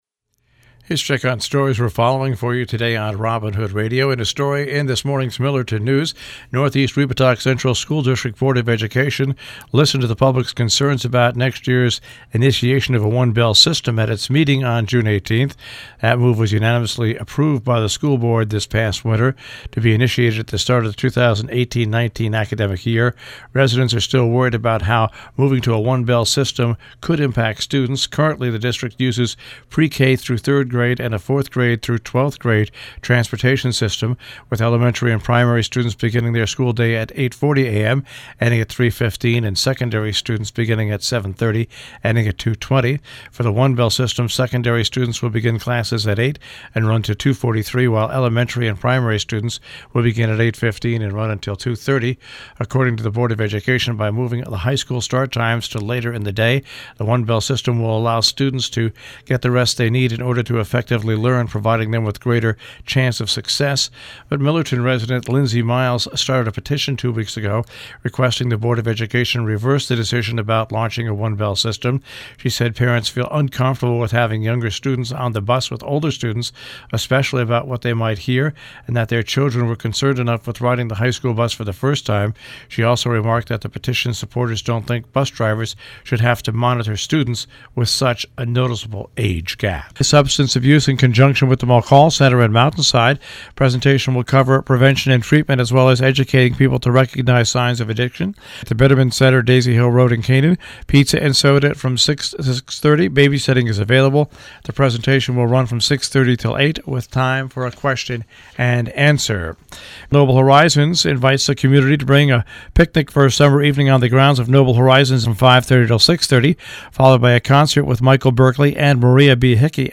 covers news and announcements in the Tri-State Region on The Breakfast Club on Robin Hood Radio